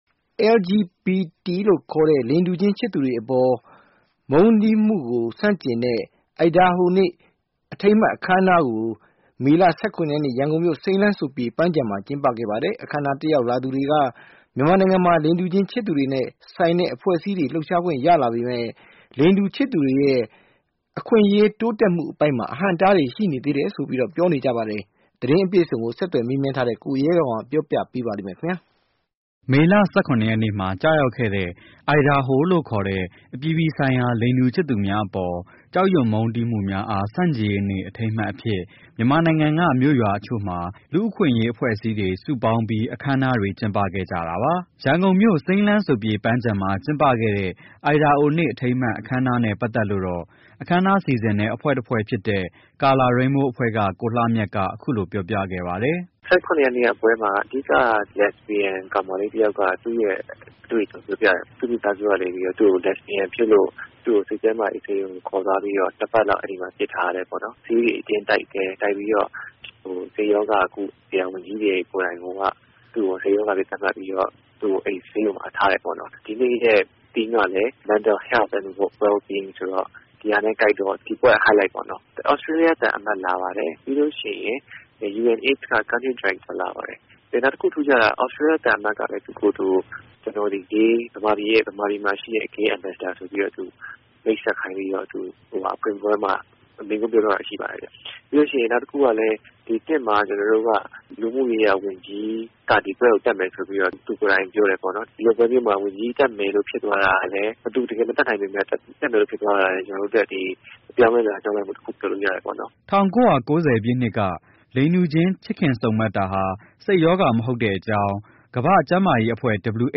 မြန်မာနိုင်ငံမှာ လိင်တူချစ်သူတွေကို ကန့်သတ်ထားတဲ့ ဥပဒေတွေ ရှိနေသေးတဲ့ အကြောင်း ဒီအခမ်းအနားကို တက်ရောက် မိန့်ခွန်းပြောခဲ့တဲ့ မြန်မာနိုင်ငံ လူ့အခွင့်အရေး ကော်မရှင်က ဒေါက်တာ ဉာဏ်ဇော်က ခုလို ပြောသွားပါတယ်။